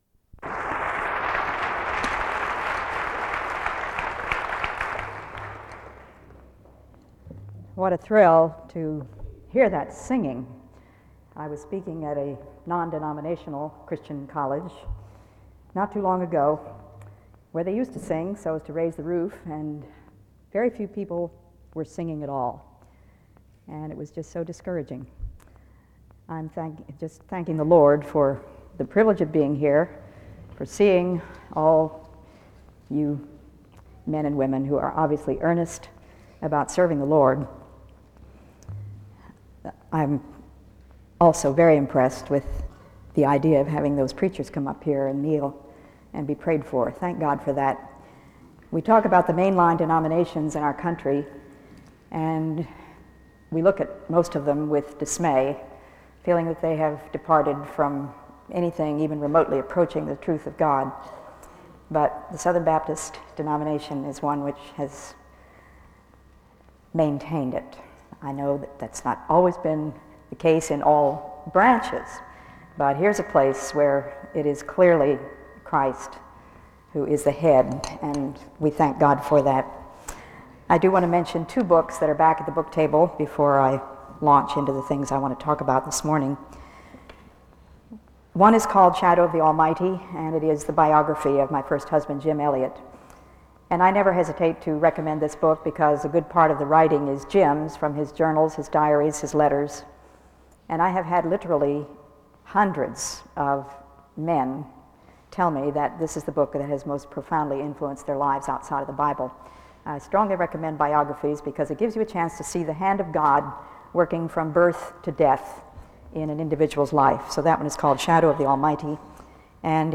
SEBTS Chapel - Elisabeth Elliot February 22, 1996 AM